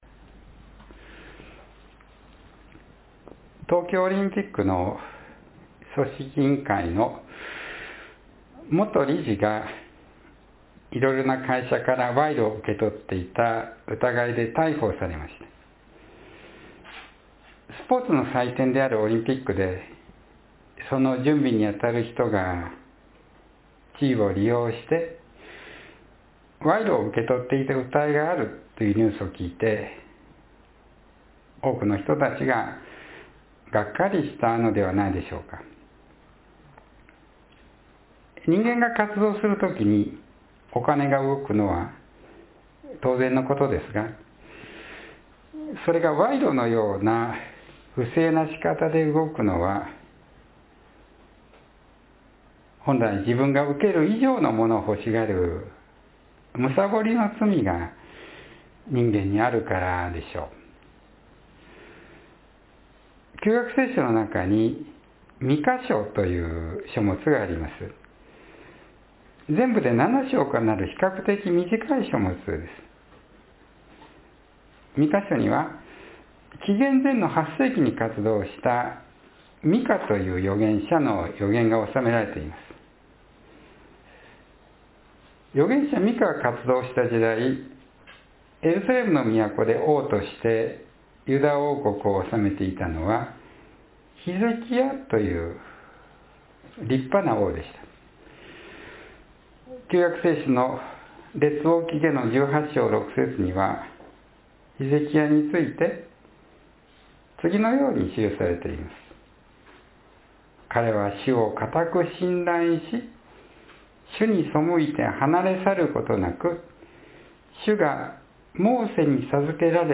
（9月25日の説教より）